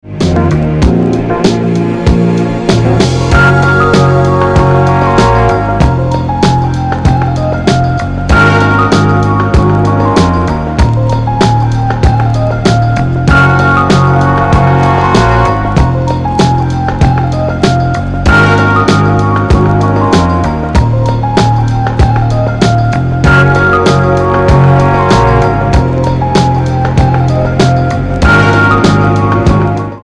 A lovely 7″ slab of indie electronica.